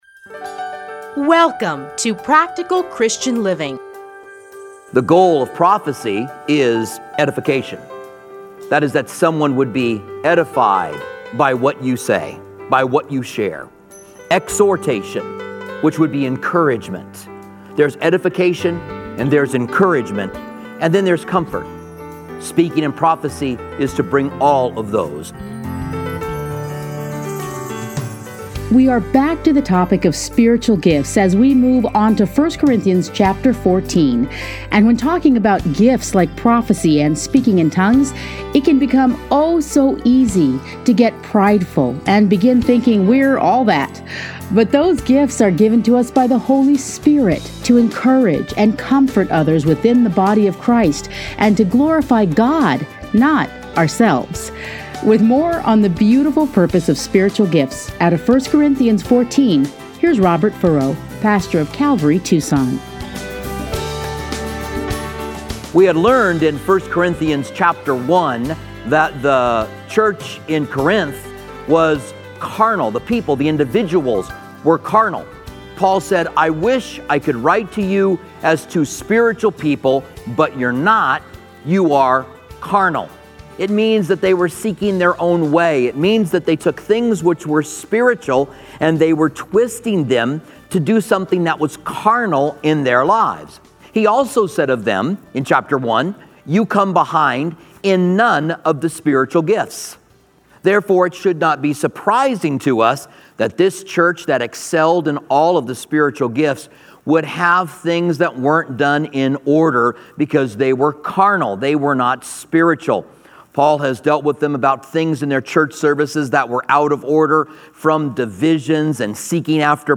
Listen here to a teaching from 1 Corinthians.